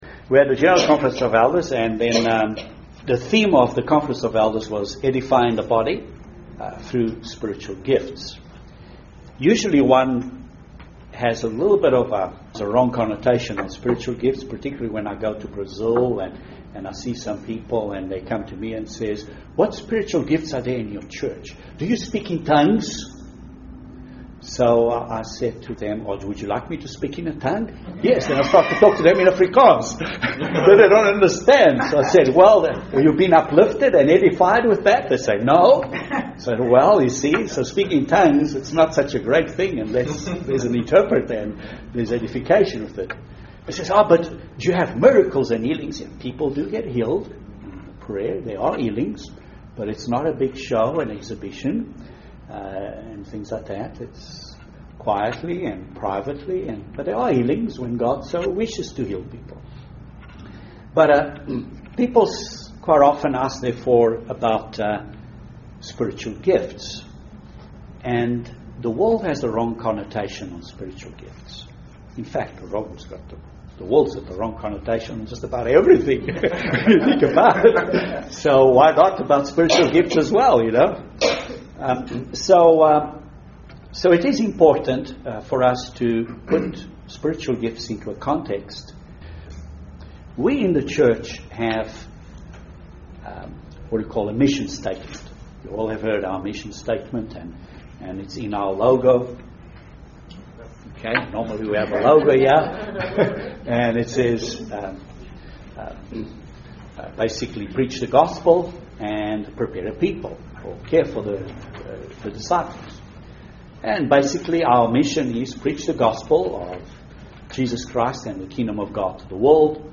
Spiritual gifts are given to us for the purpose of building and edifying the Church UCG Sermon Transcript This transcript was generated by AI and may contain errors.